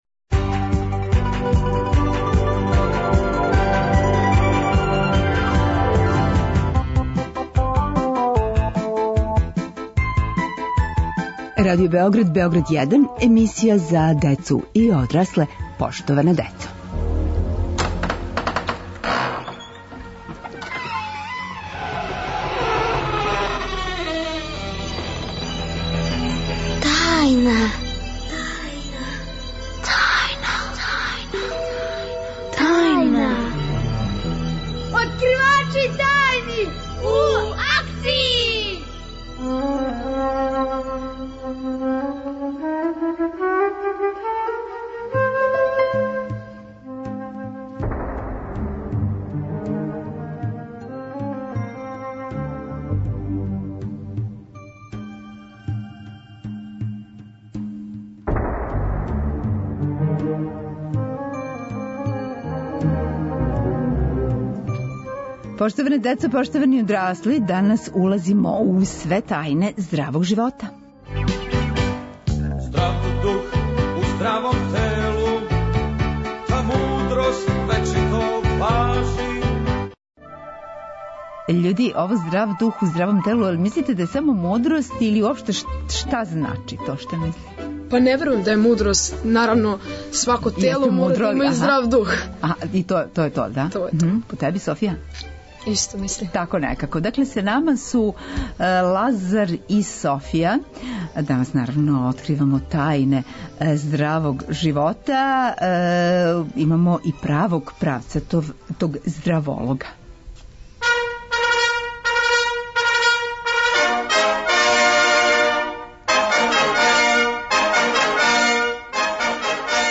Откривамо све тајне здравог живота. Гости - деца